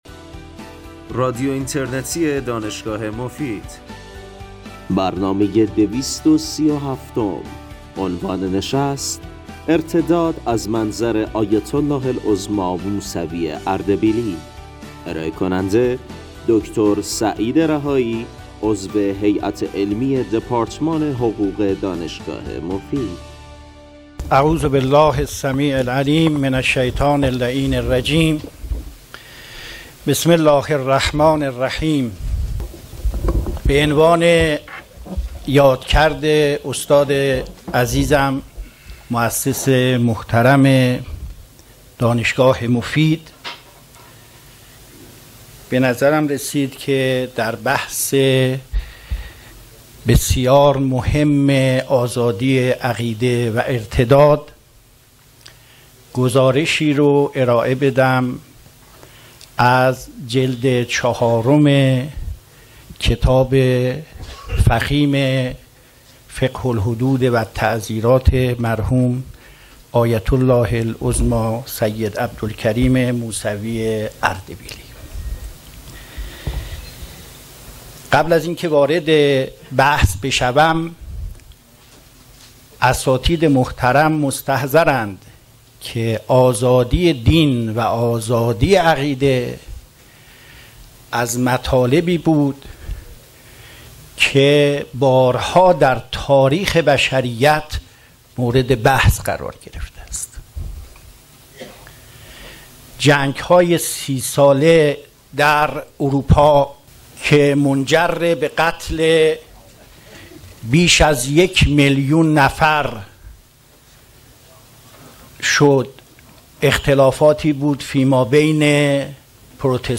سخنرانی
در همایش دومین نکوداشت موسس فقید دانشگاه مفید